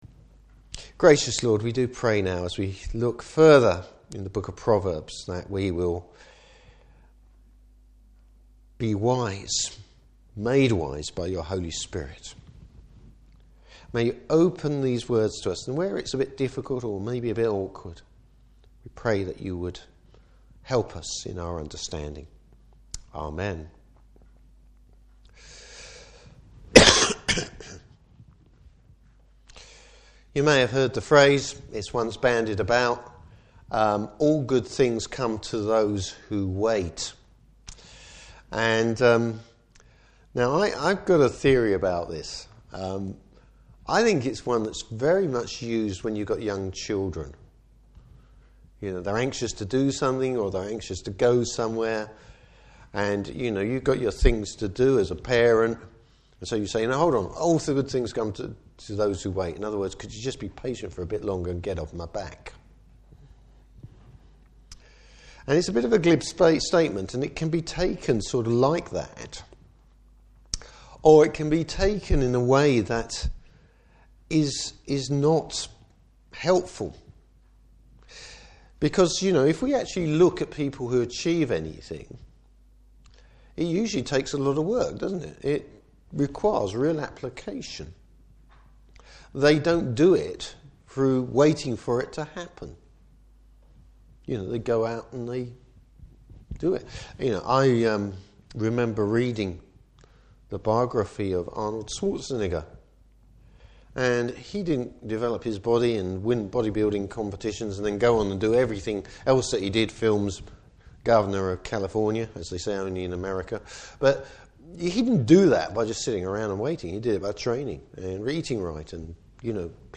Service Type: Morning Service Being grounded in God’s Word to provide our moral framework .